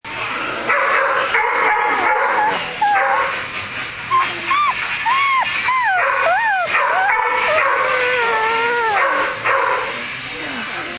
Barking Whining Efecto de Sonido Descargar
Barking Whining Botón de Sonido